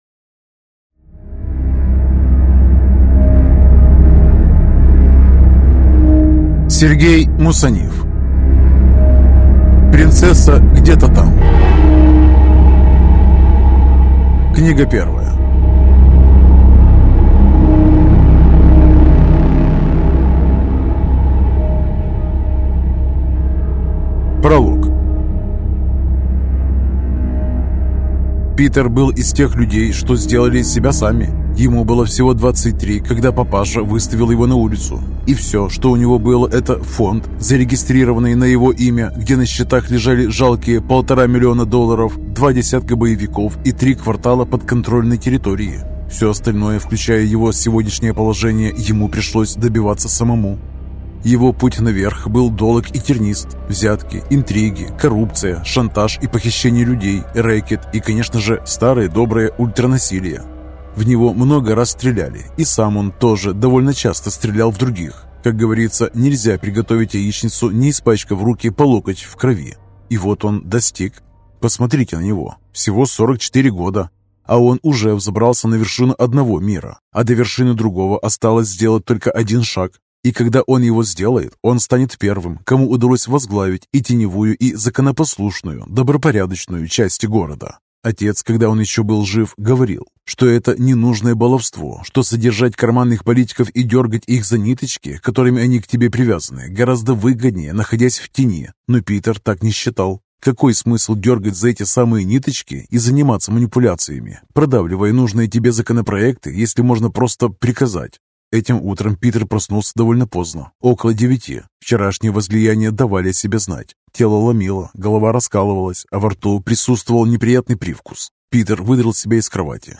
Аудиокнига Принцесса где-то там. Книга 1 | Библиотека аудиокниг